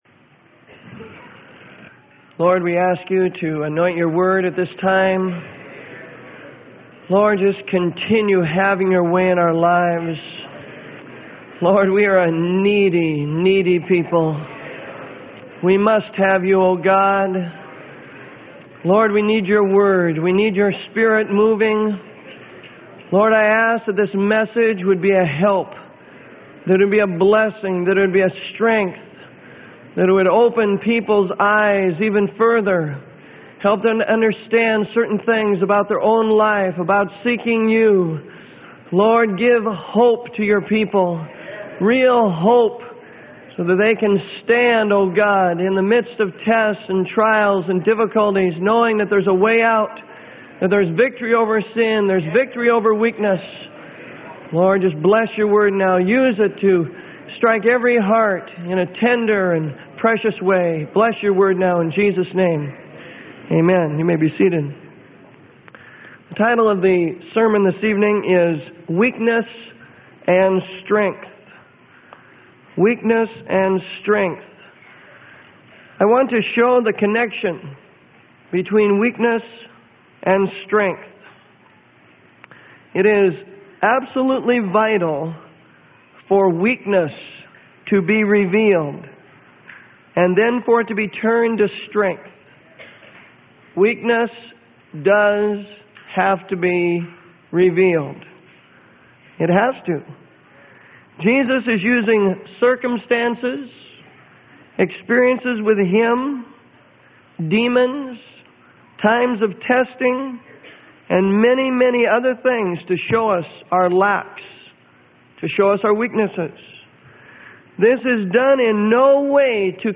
Sermon: Weakness And Strength.